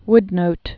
(wdnōt)